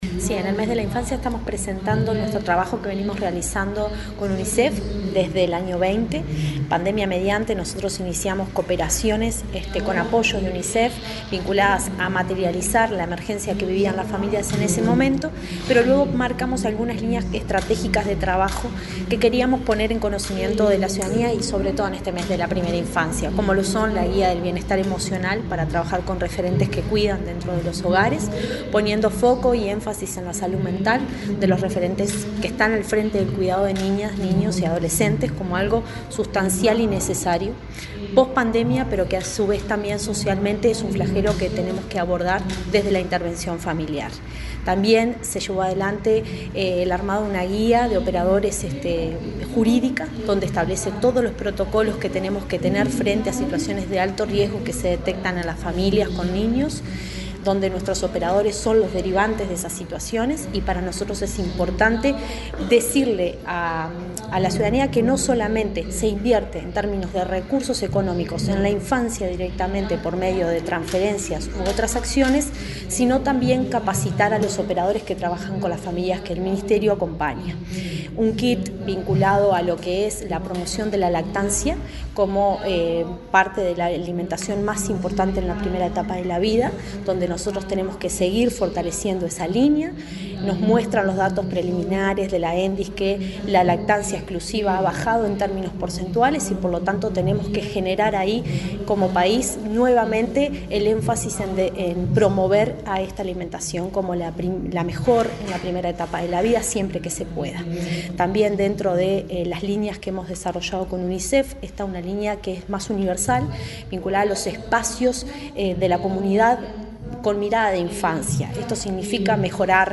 Declaraciones de la directora nacional de Desarrollo Social, Cecilia Sena
La directora nacional de Desarrollo Social, Cecilia Sena, dialogó con la prensa, luego de la presentación de las líneas de cooperación de Unicef con